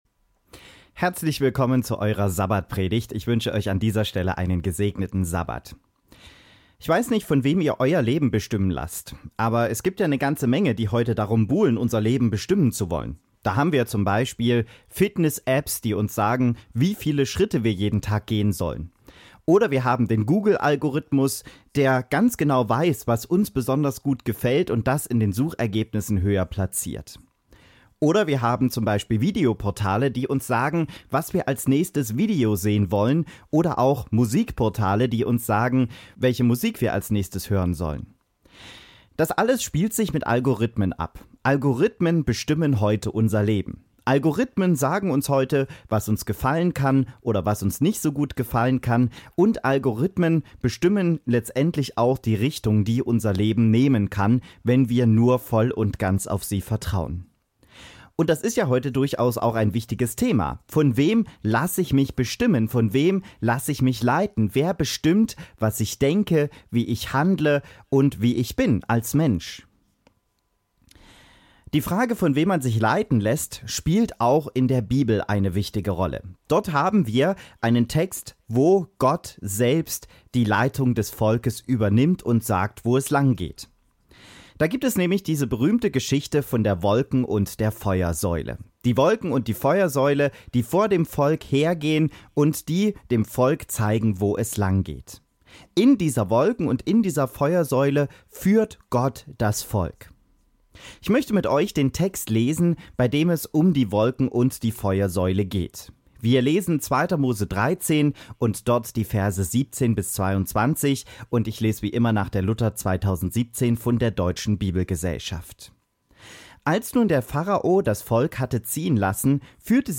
In dieser Predigt geht es darum, wie Gott das Volk Israel durch die Wolken- und Feuersäule geführt hat und was wir dabei für uns heute und die Führung Gottes ableiten können.